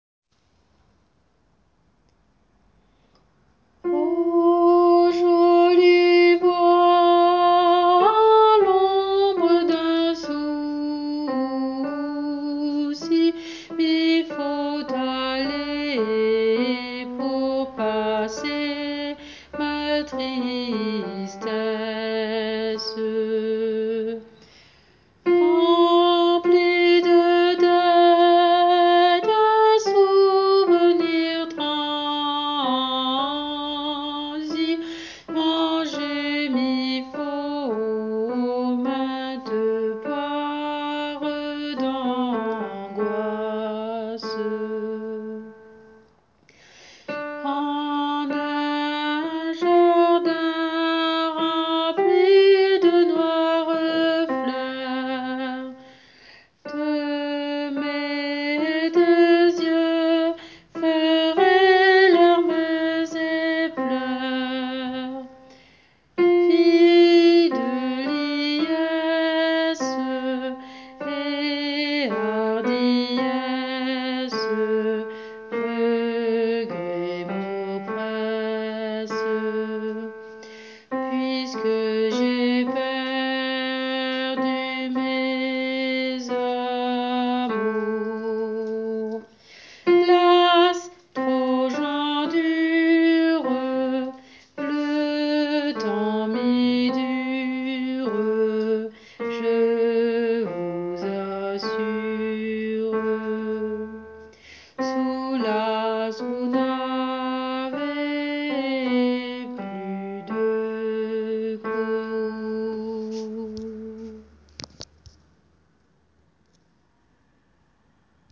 [private role=”subscriber”][/private]Alto :
joli-bois-alto.wav